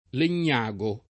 Legnago [ len’n’ #g o ]